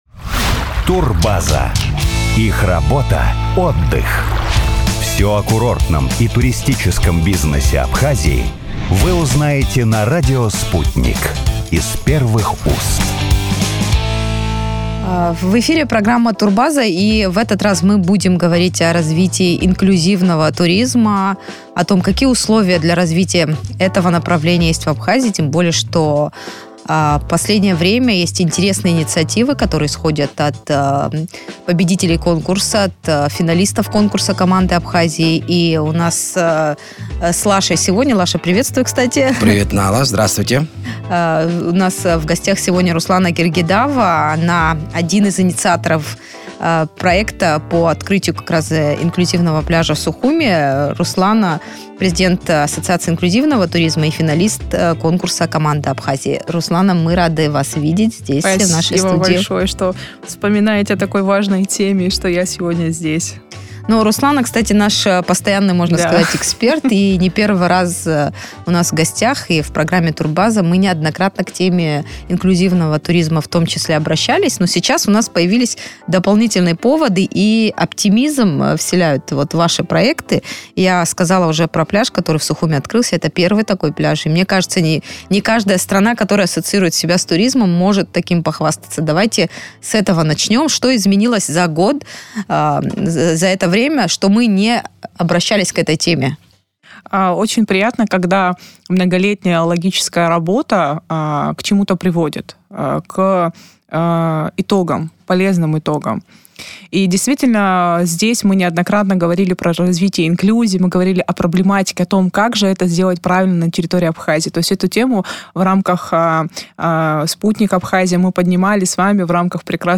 В эфире радио Sputnik она рассказала о том, какая работа ведется для создания условий людям с ограниченными физическими возможностями.